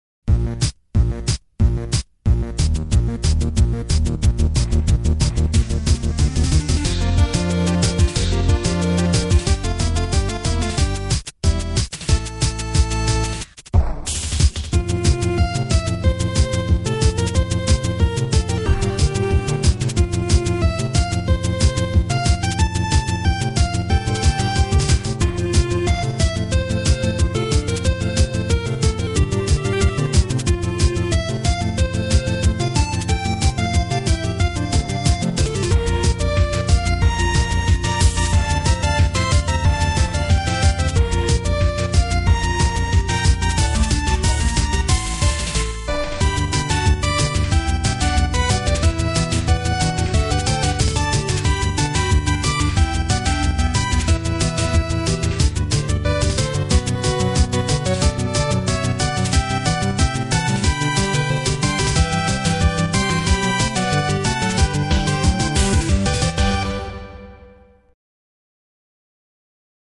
（音質　16kbps〜48kbps　モノラル）